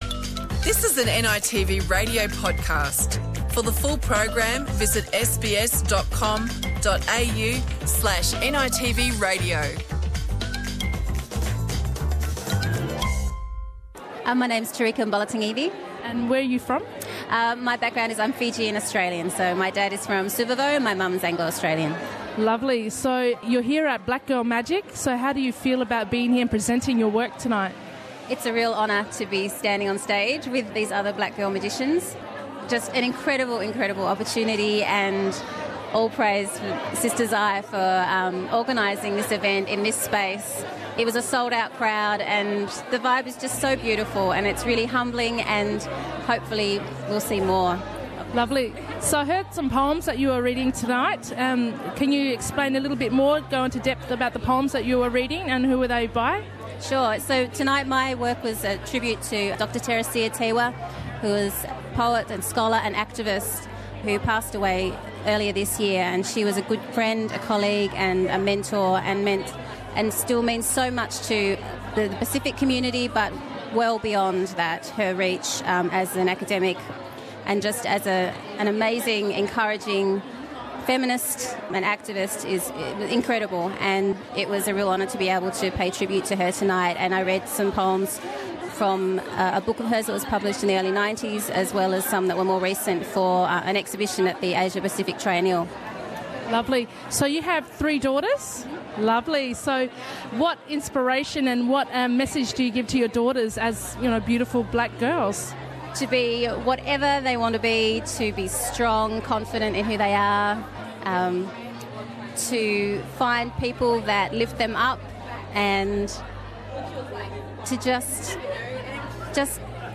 Listen in to the Black Girl Magic Podcast to hear the women's experiences on the night.